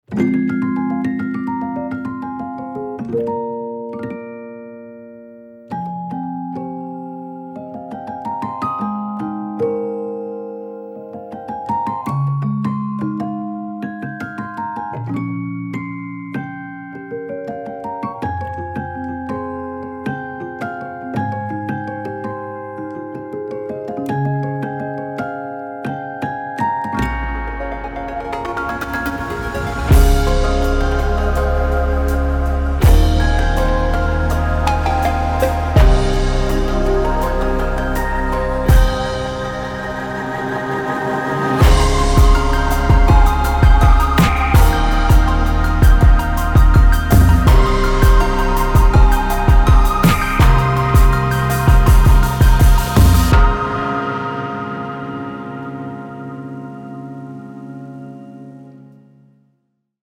DE CHARMANTS TIMBRES CRISTALLINS
• Des instruments vintage restaurés à neuf